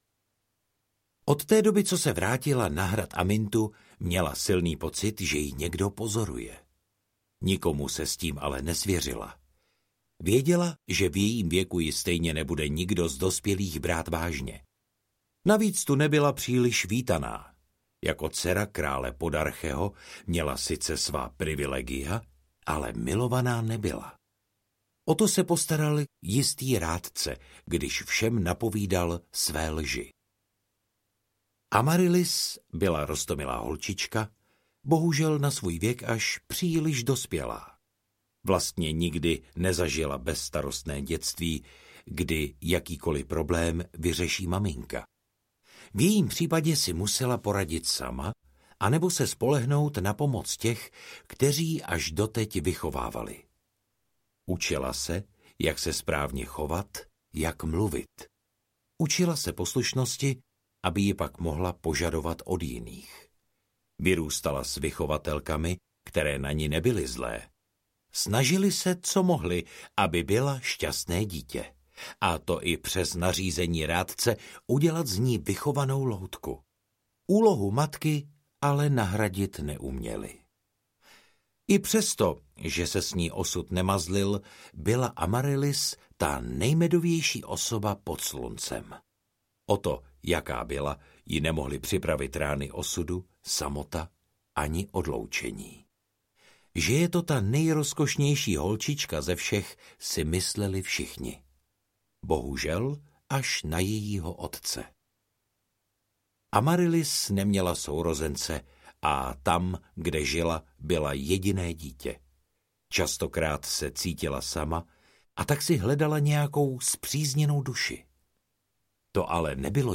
Amarylis z Aminty audiokniha
Ukázka z knihy